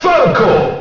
The announcer saying Falco's name in Super Smash Bros. Melee.
Falco_Announcer_SSBM.wav